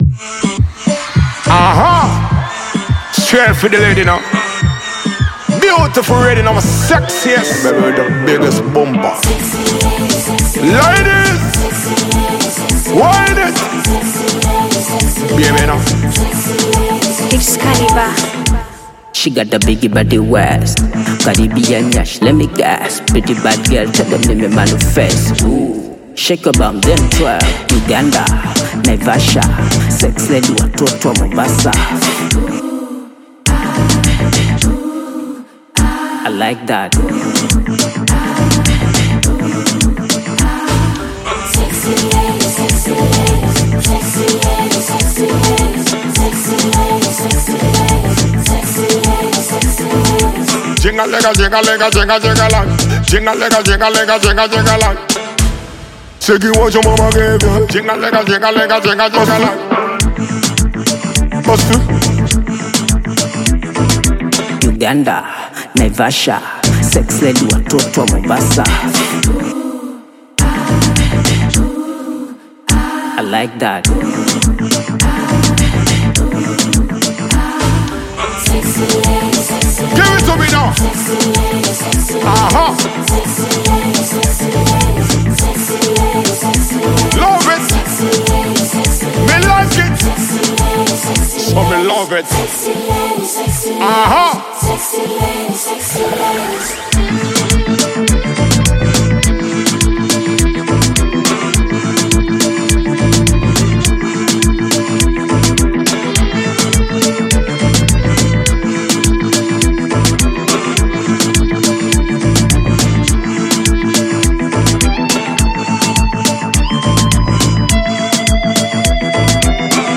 With its infectious beat and captivating vocals